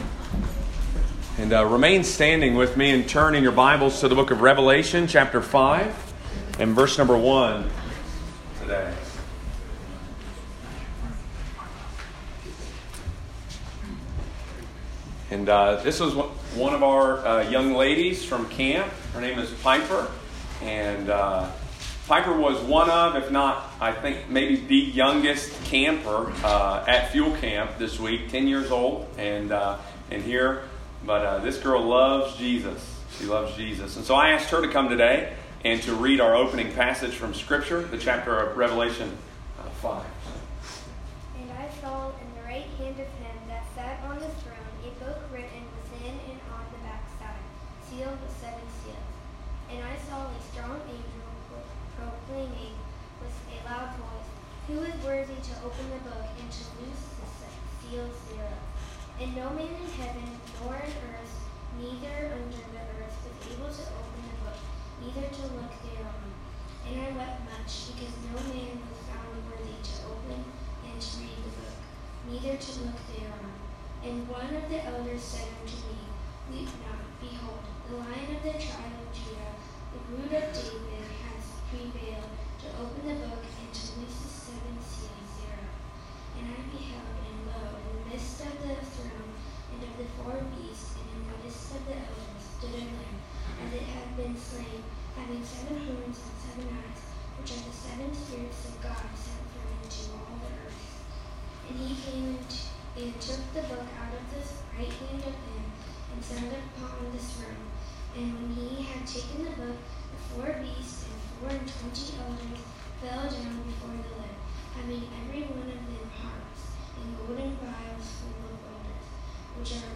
reads our opening passage.